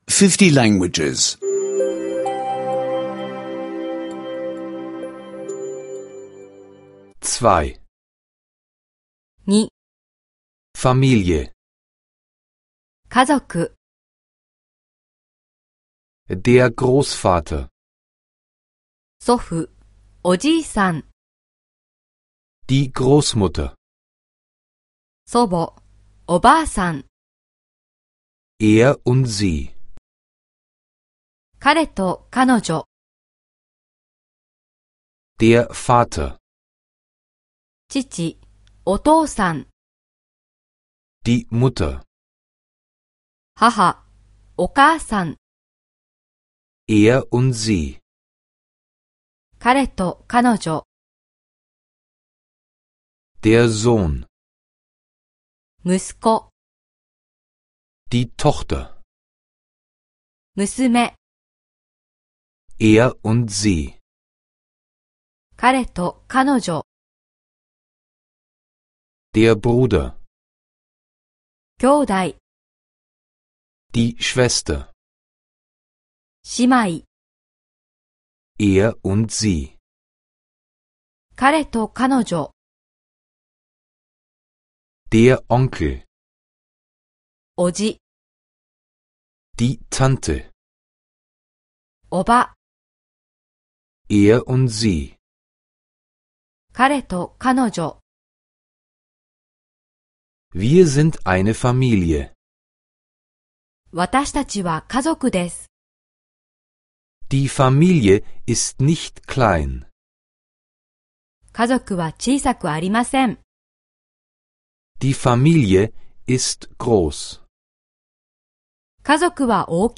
Japanisch Sprache-Audiokurs (kostenloser Download)